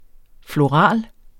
Udtale [ floˈʁɑˀl ]